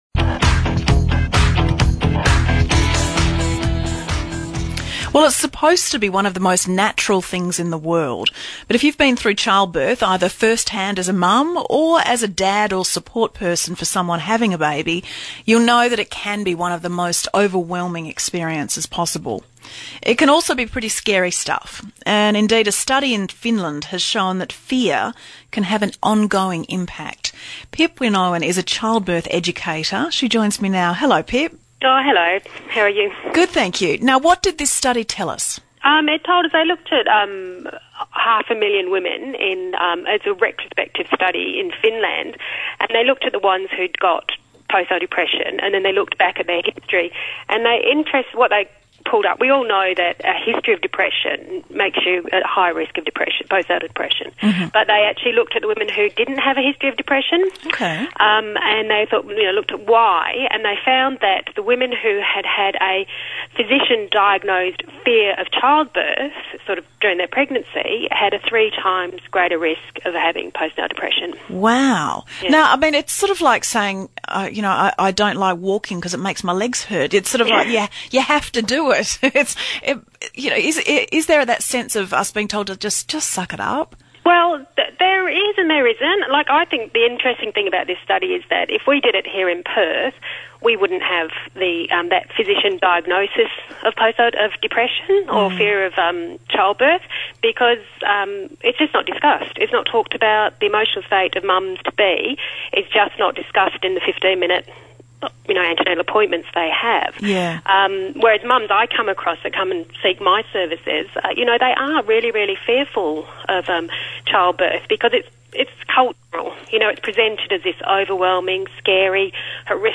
Here is an interview I did about how the fear of childbirth can increase you risk of postnatal depression.